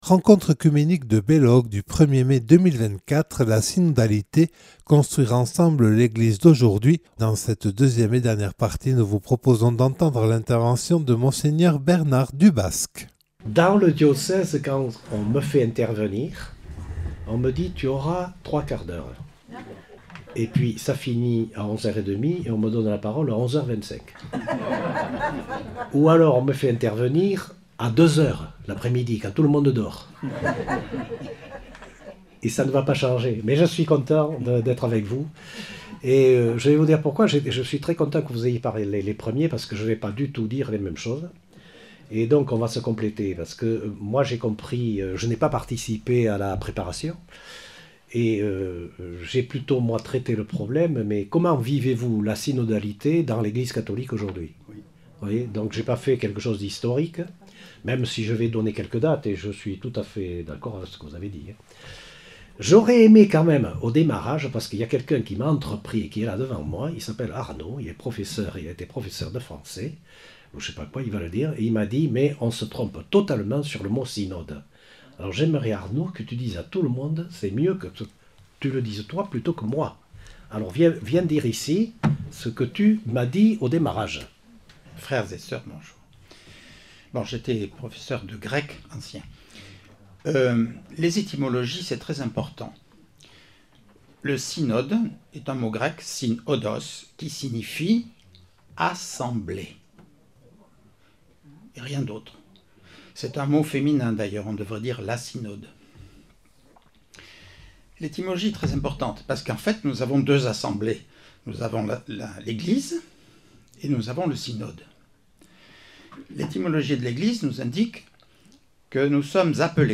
Rencontre oecuménique du 1er mai 2024 à Belloc consacrée à la synodalité vue et vécue par nos Eglises. Intervention de Mgr Bernard Dubasque.